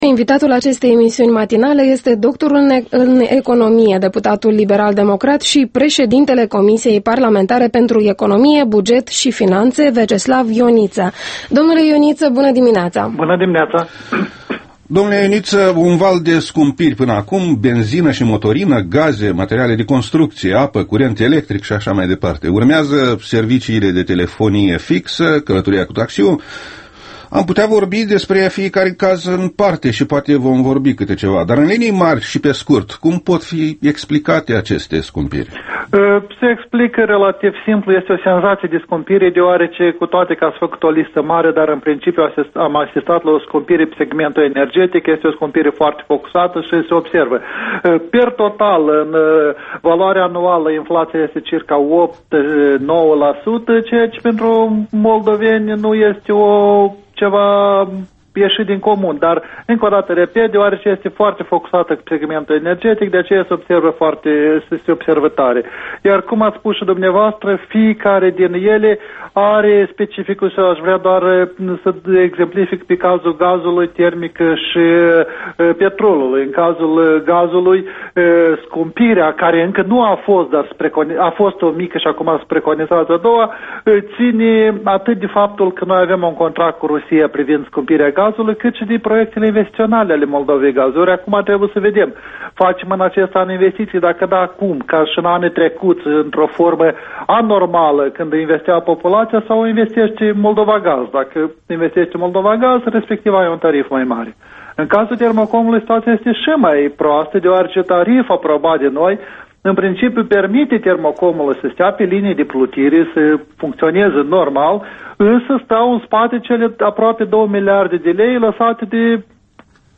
Interviu matinal EL: cu Veaceslav Ioniță